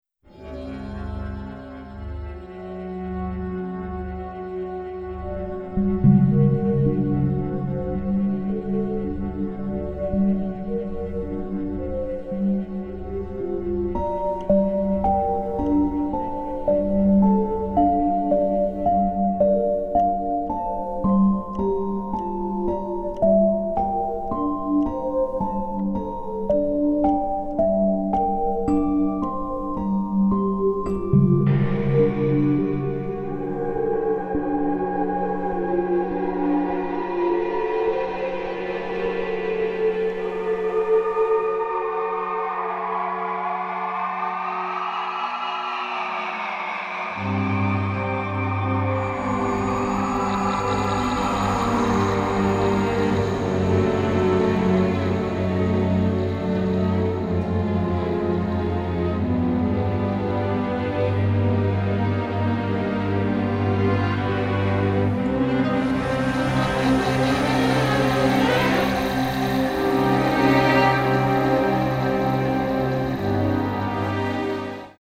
orchestra and chorus